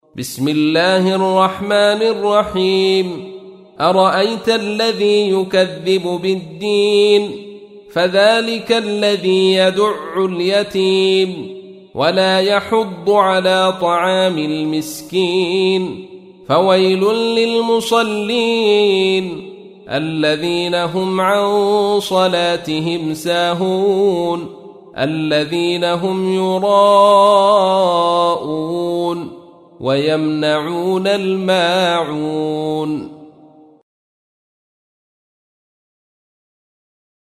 سورة الماعون / القارئ عبد الرشيد صوفي / القرآن الكريم / موقع يا حسين